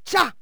valkyrie_attack1.wav